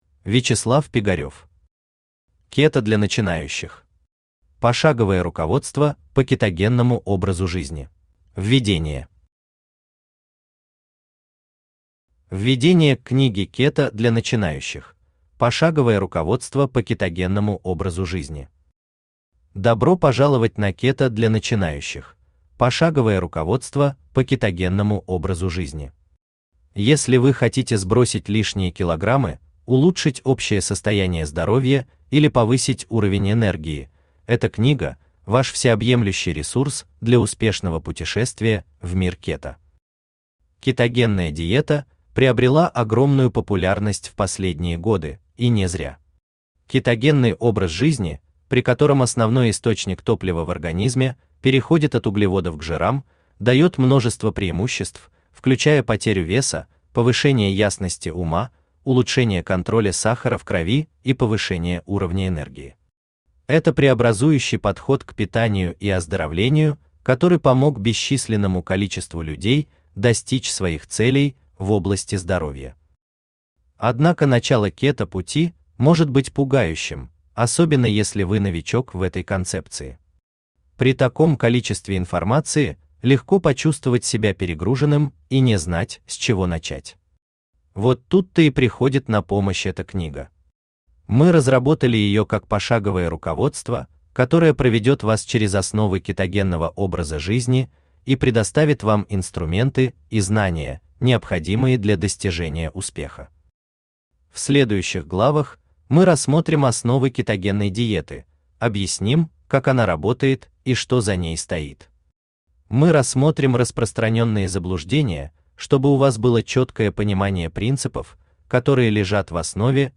Аудиокнига Кето для начинающих. Пошаговое руководство по кетогенному образу жизни | Библиотека аудиокниг
Пошаговое руководство по кетогенному образу жизни Автор Вячеслав Пигарев Читает аудиокнигу Авточтец ЛитРес.